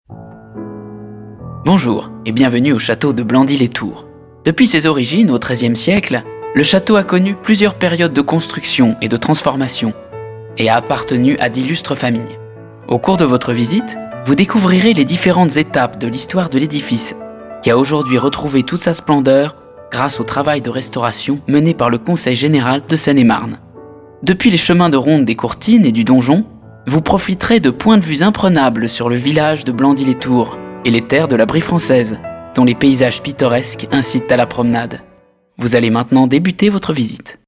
Comédien à la voix jeune,medium, très à l'aise dans la comédie mais aussi voice over, documentaire, etc.
Sprechprobe: Sonstiges (Muttersprache):
My voice is young, dynamic and i'm very much at ease with comedy